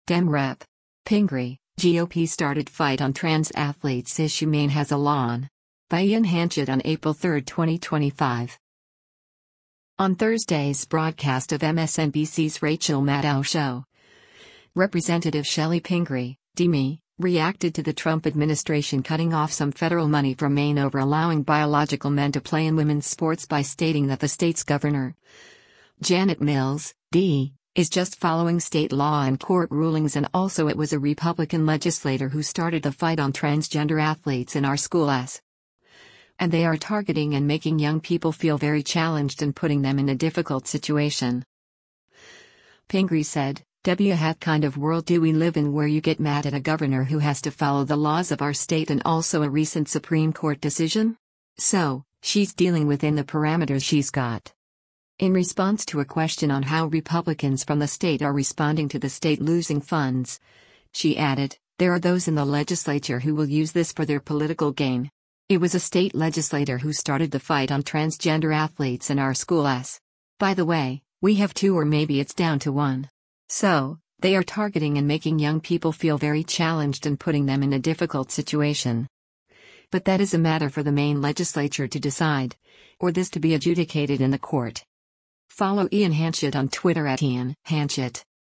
On Thursday’s broadcast of MSNBC’s “Rachel Maddow Show,” Rep. Chellie Pingree (D-ME) reacted to the Trump administration cutting off some federal money from Maine over allowing biological men to play in women’s sports by stating that the state’s Governor, Janet Mills, (D) is just following state law and court rulings and also it was a Republican legislator “who started the fight on transgender athletes in our school[s].”